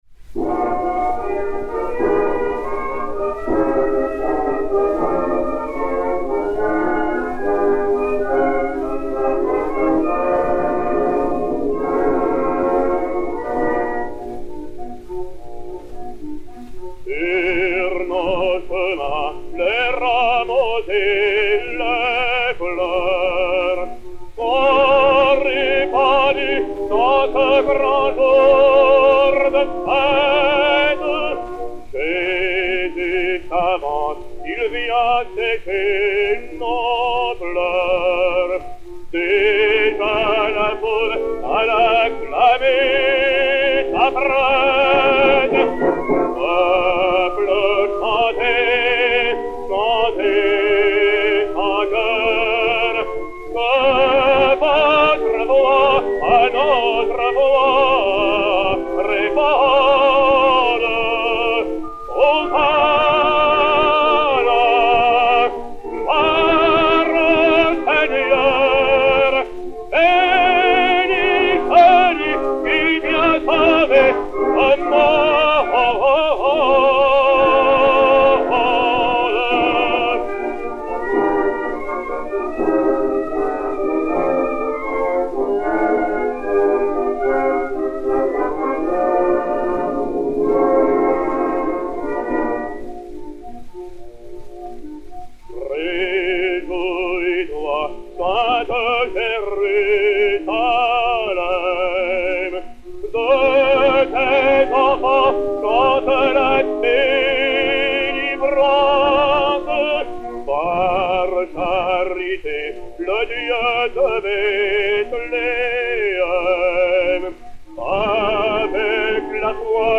Hymne, poésie de Jules BERTRAND, musique de Jean-Baptiste FAURE (1864).
Jean Noté et Orchestre
Zonophone X-82481, mat. 9103u, enr. à Paris en 1905